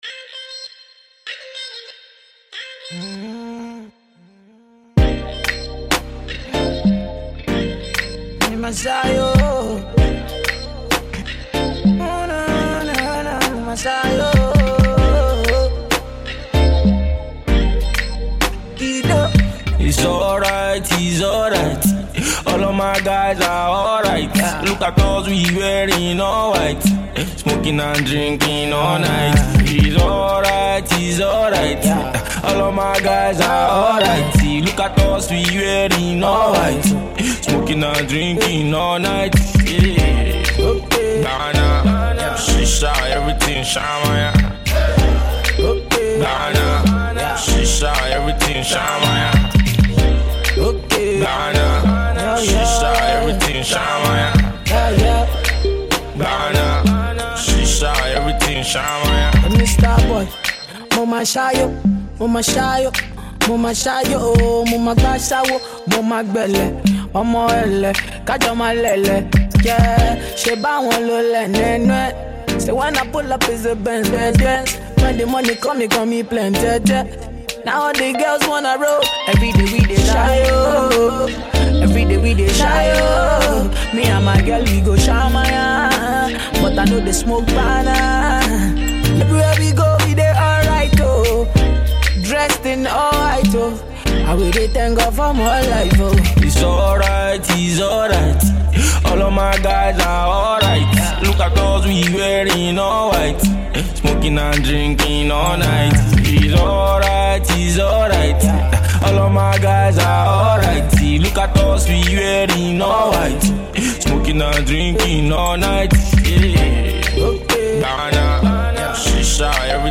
Afro Soul song